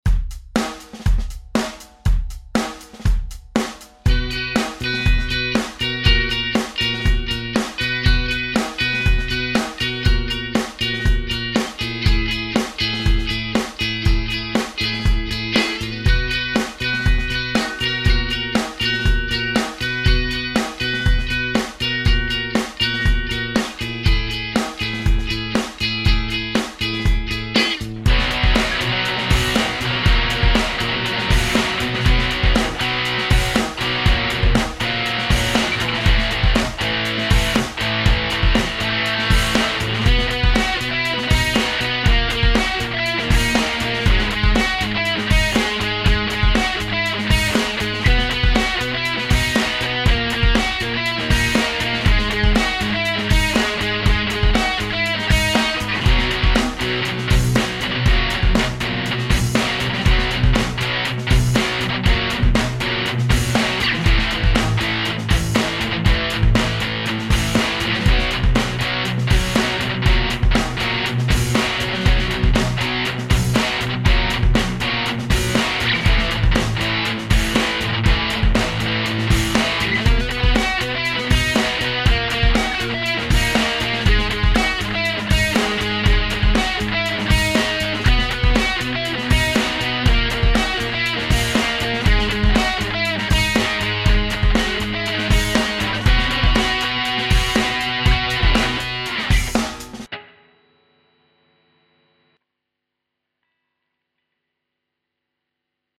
Это Наброски и демо записи.